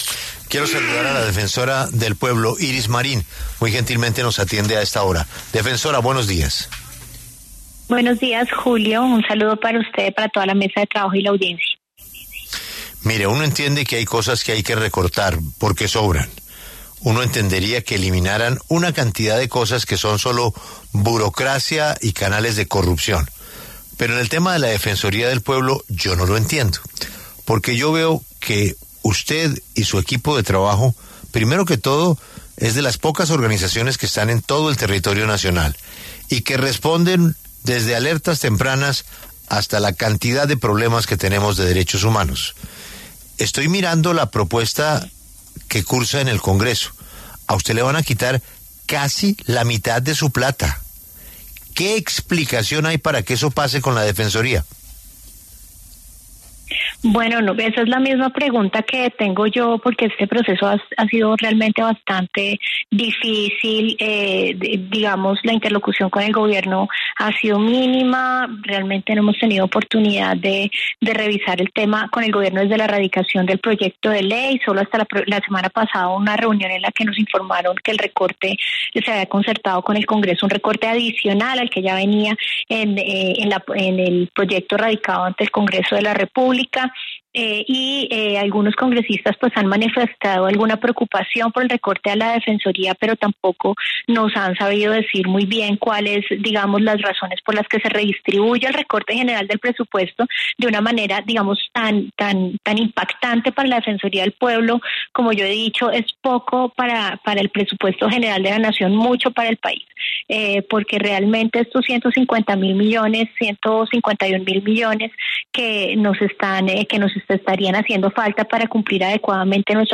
En entrevista con La W Radio, Marín señaló que la interlocución con el Gobierno ha sido “mínima” y que la entidad no fue consultada antes de que se presentara la ponencia que ahora cursa en el Congreso.
Durante la entrevista, Julio Sánchez Cristo, director de La W, le planteó a Marín si su postura independiente frente a temas del Gobierno podría haber influido en esta decisión.